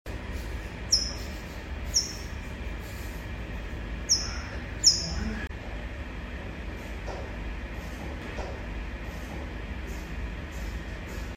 🔉Sound on! The new cheetah sound effects free download
The new cheetah Mp3 Sound Effect 🔉Sound on! The new cheetah quintuplets at the Zoo have the most adorable chirps! They're learning to vocalize and communicate, which is an important part of their development.